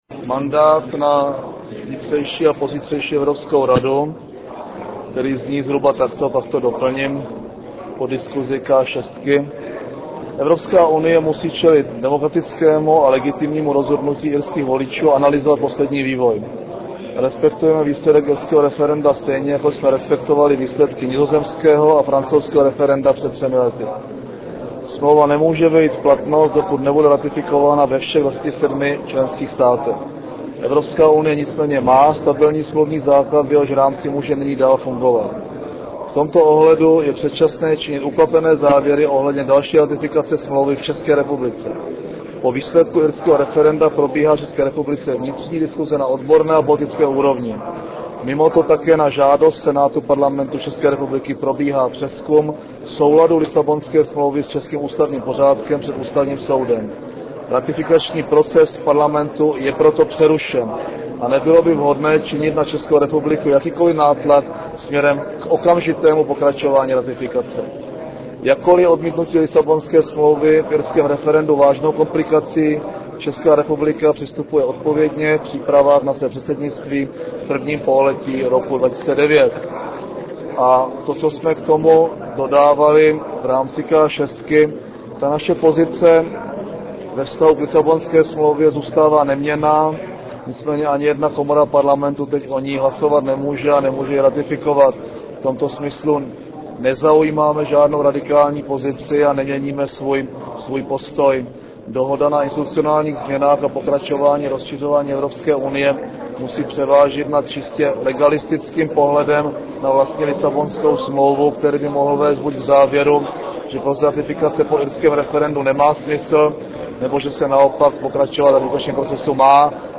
Briefing premiéra Mirka Topolánka k Lisabonské smlouvě - 18.6.